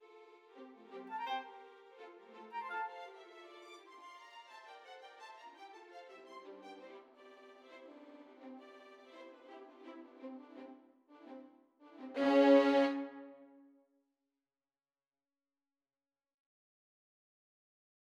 바장조, 2/2박자, 자유로운 론도 형식(A-B-A'-A-A"-C-A-B-코다)이다. 소나타 형식으로 볼 수도 있으며, 실제로 그렇게 해설되는 경우도 있다.[15] 이 경우 작품의 거의 절반에 해당하는 전반부는 완전히 소나타 형식이므로(A'가 전개부) 후반의 절반 모두가 코다가 되는데(A' 이후), 베토벤 교향곡에서 최종 악장의 코다는 집요하고 장대해지는 경향이 있다. 6잇단음표에 의한 "타타타타타타"의 리듬을 특징으로 하며, 강약이 심하게 바뀐다.
피날레 1-17마디